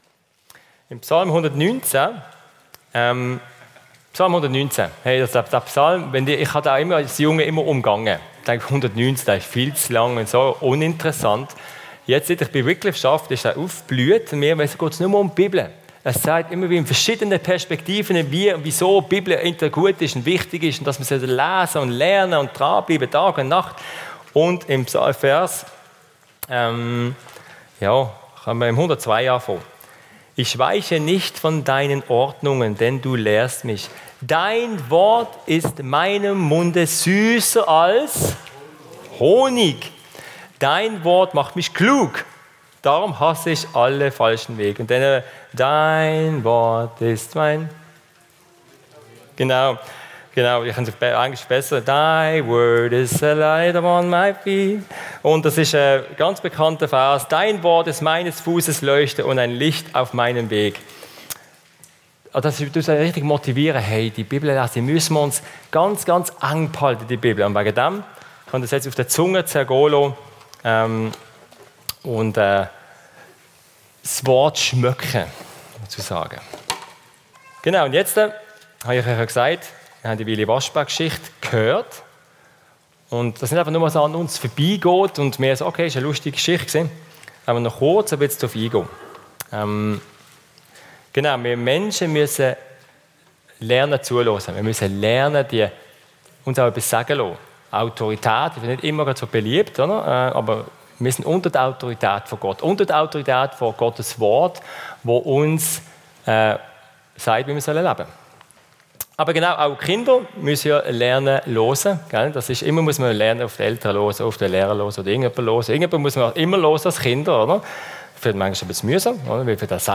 Generationen-Gottesdienst mit Wycliff ~ Steinekirche Podcast